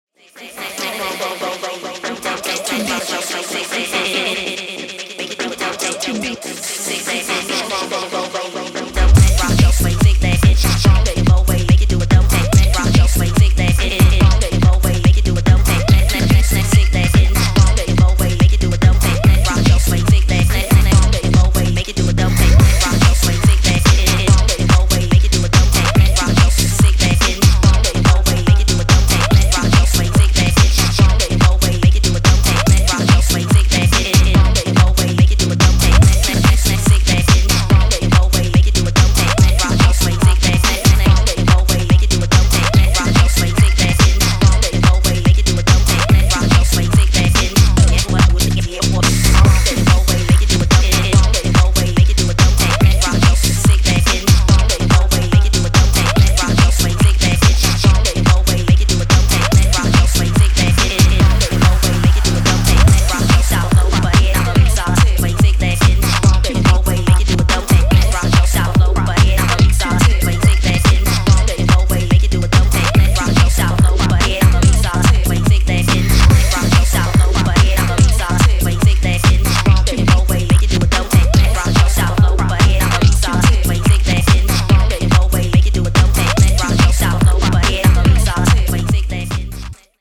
自身のラップをリズミカルにチョップ、トッピングしたパンピン・ハード・ローラー